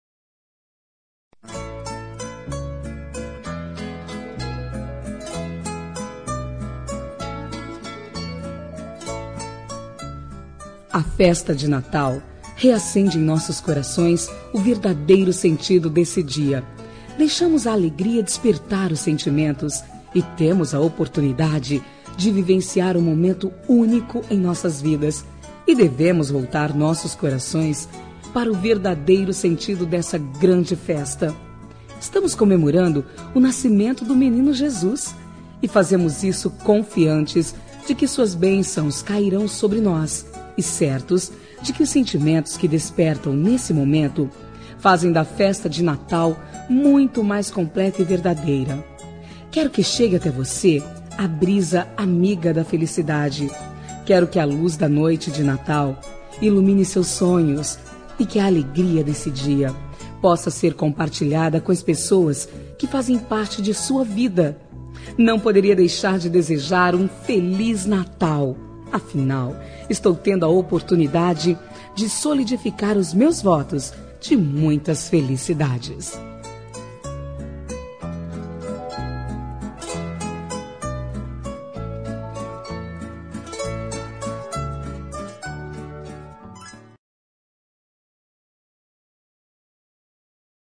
Natal Pessoa Especial – Voz Feminina – Cód: 348222